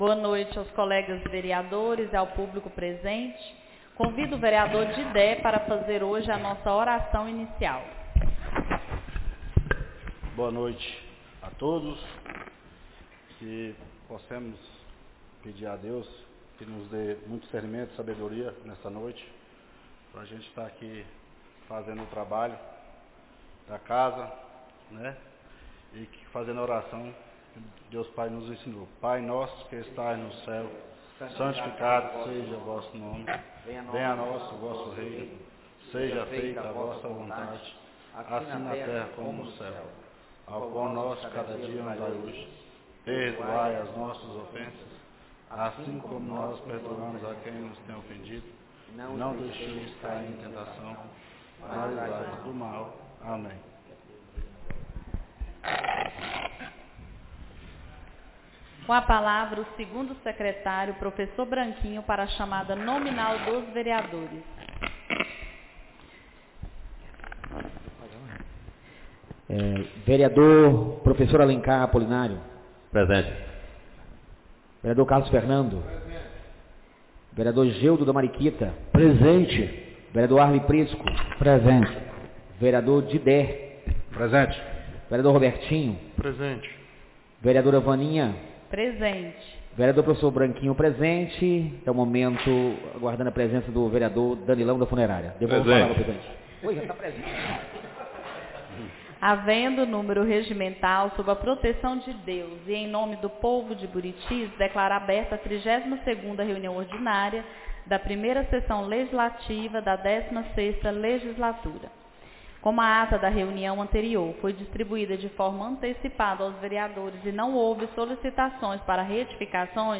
32ª Reunião Ordinária da 1ª Sessão Legislativa da 16ª Legislatura - 22-09-25